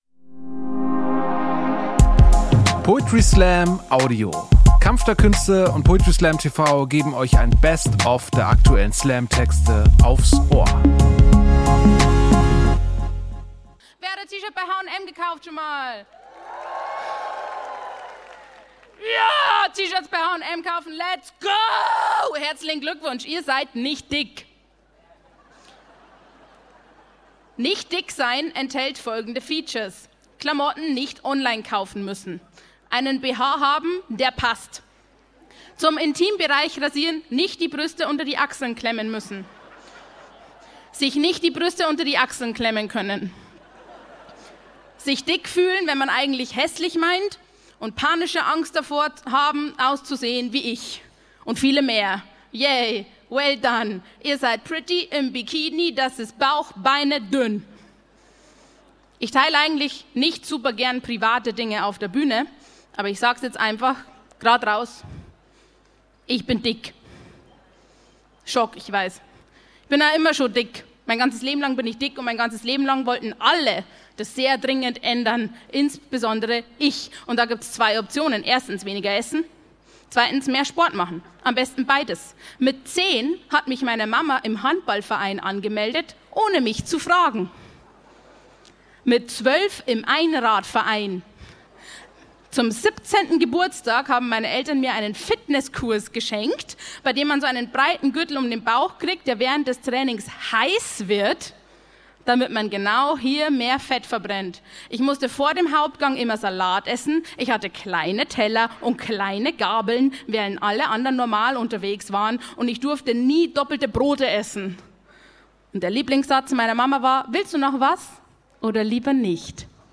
Stage: Stadtpark Hamburg